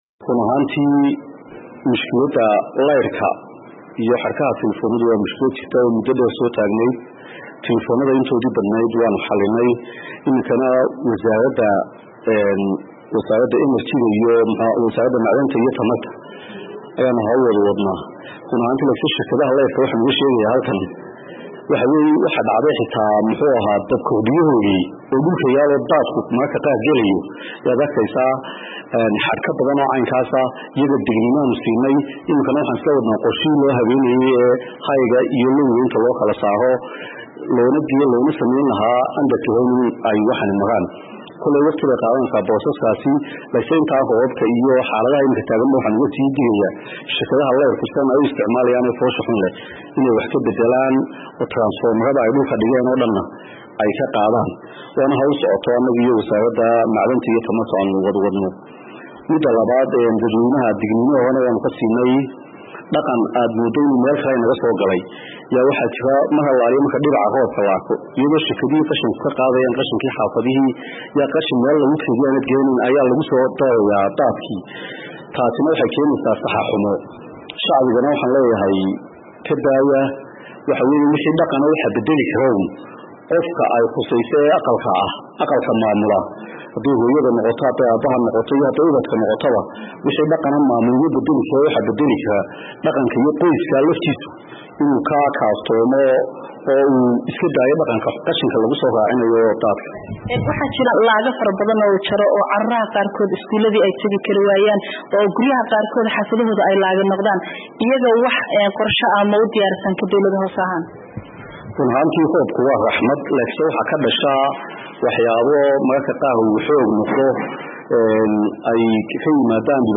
Wareysi: Saameynta Roobki ka Da'ay Hargeysa